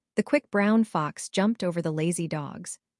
Kokoro-FastAPI - Dockerized FastAPI wrapper for Kokoro-82M text-to-speech model w/CPU ONNX and NVIDIA GPU PyTorch support, handling, and auto-stitching
speech.mp3